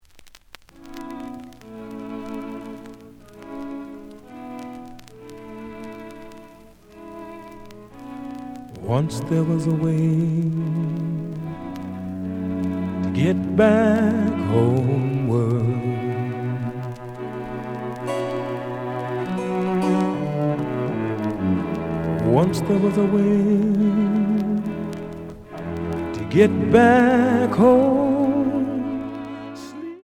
(Mono)
The audio sample is recorded from the actual item.
●Genre: Jazz Rock / Fusion